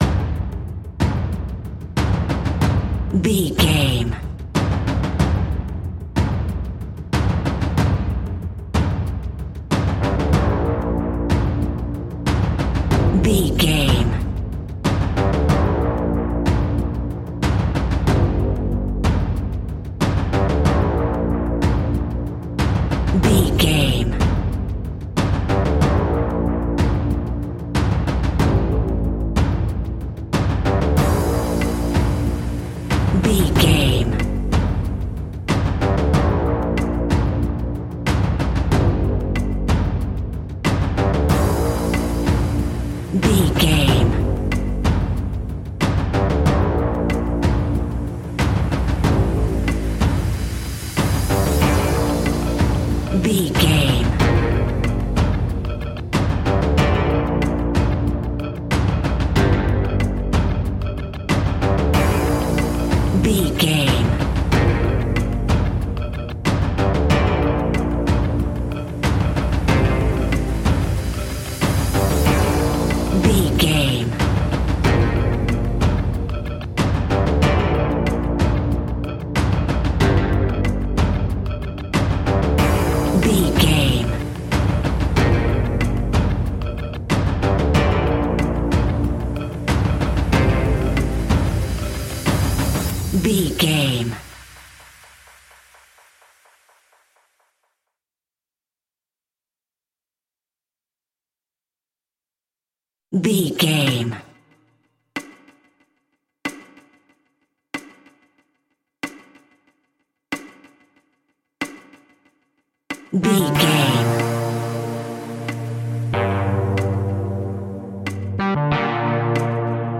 royalty free music
Aeolian/Minor
ominous
dark
haunting
eerie
brass
drums
synthesiser
ticking
electronic music